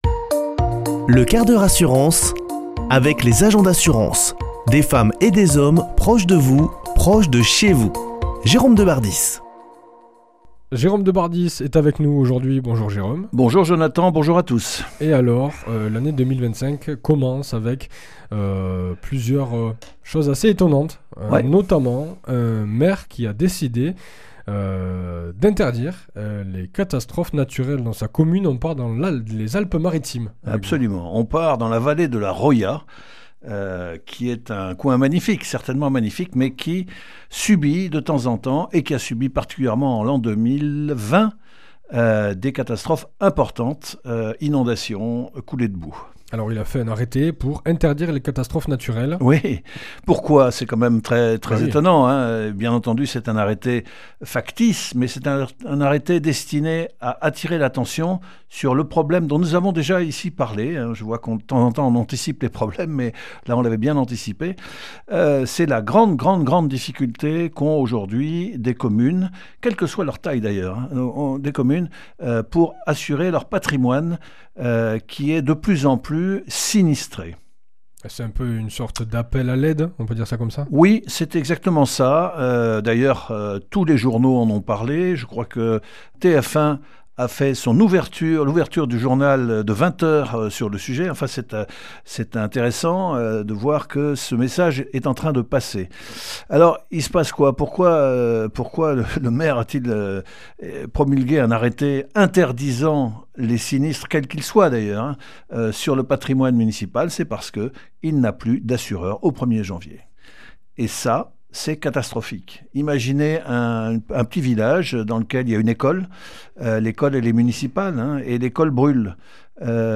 mardi 14 janvier 2025 Chronique le 1/4 h assurance Durée 5 min
Chroniqueur